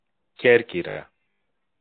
Corfu (/kɔːrˈf(j)/ kor-FEW, -FOO, US also /ˈkɔːrf(j)/ KOR-few, -foo) or Kerkyra (Greek: Κέρκυρα, romanizedKérkyra, pronounced [ˈcercira]
El-Κέρκυρα.ogg.mp3